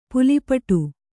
♪ puli paṭu